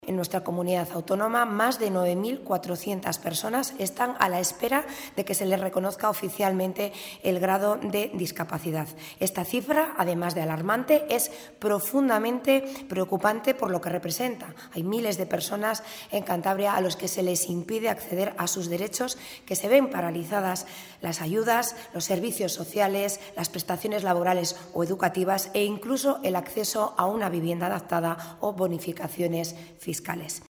Ver declaraciones de Rosa Díaz, diputada del Partido Regionalista de Cantabria y portavoz del PRC en materia de Políticas Sociales.
Rosa Díaz en la rueda de prensa que ha ofrecido hoy